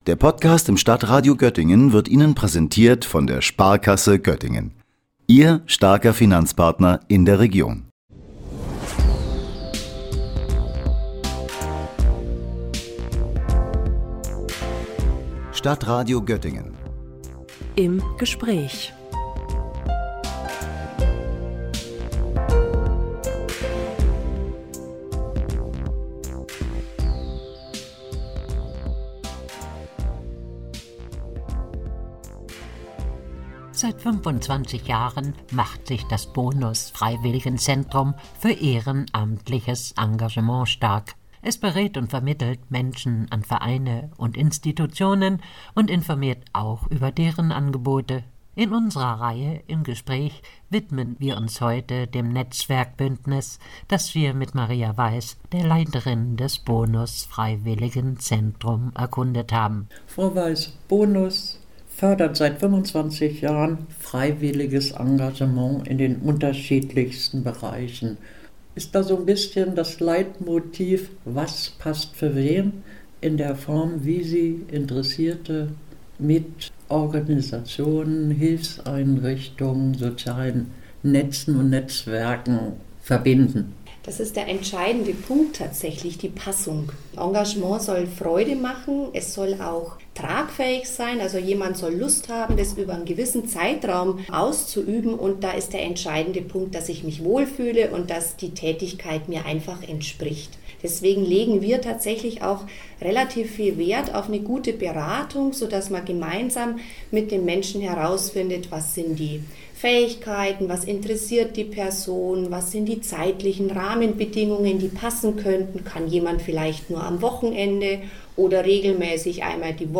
25 Jahre Freiwilligenzentrum BONUS – Gespräch